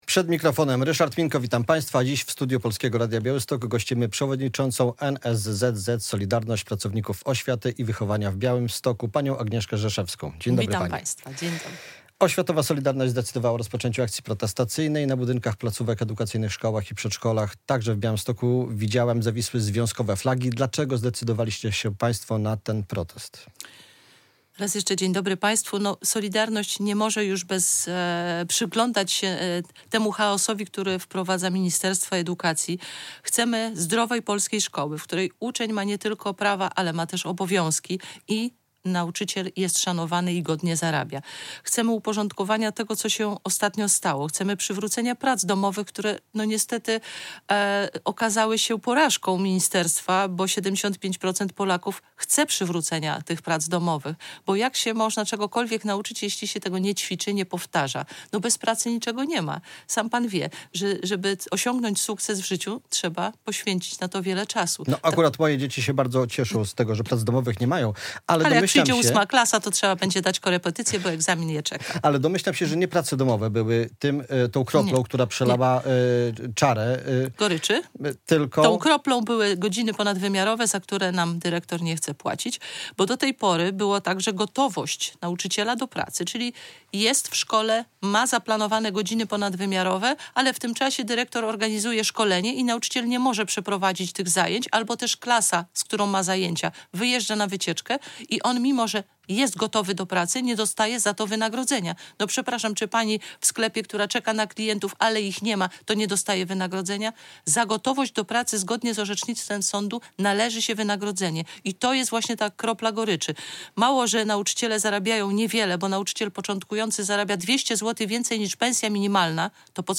Radio Białystok | Gość